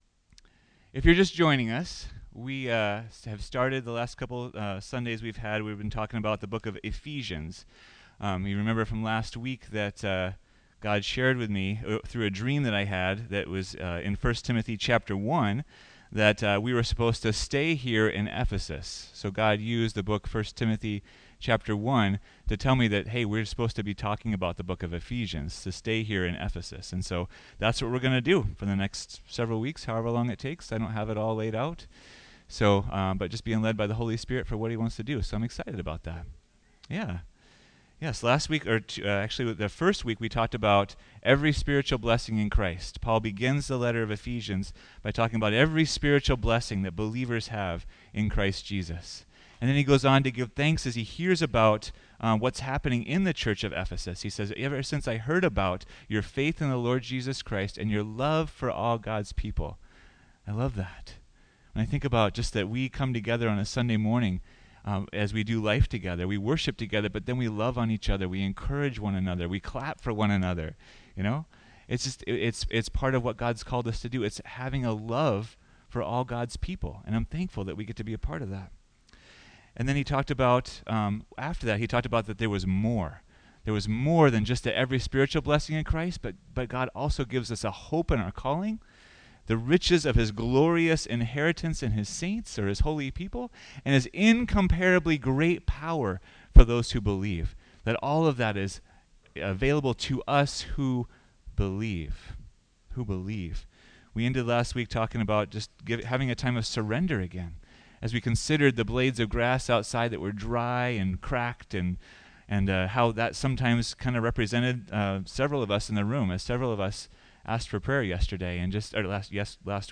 What work matters? Listen to the next message in the series on Ephesians to find out!